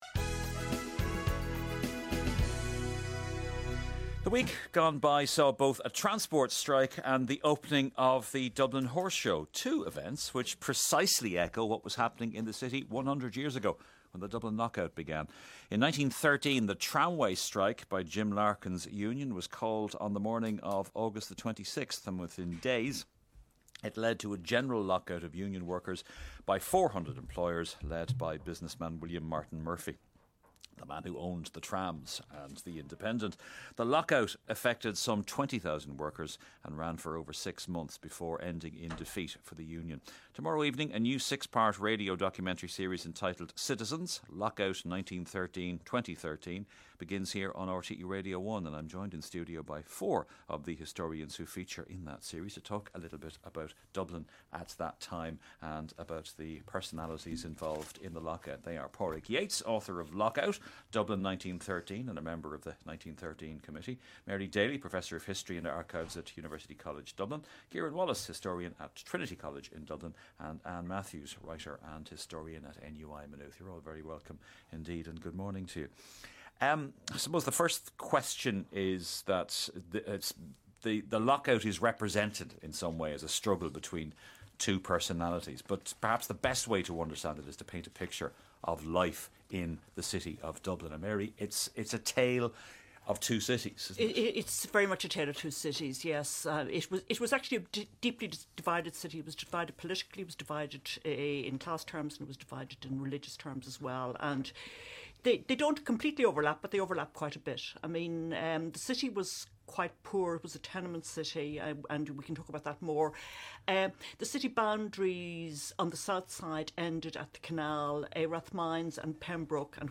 To tie in with the documentary series Citizens: Lockout 1913-2013 three live discussions about the lockout will feature on the ‘Today with Myles Dungan’ show.